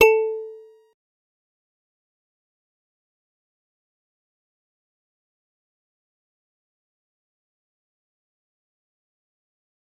G_Musicbox-A4-pp.wav